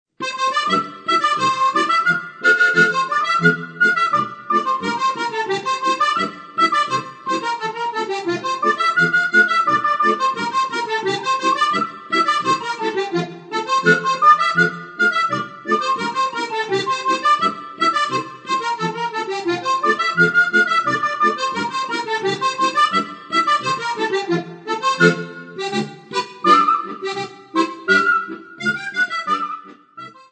Besetzung: Okarina und Steirische Harmonika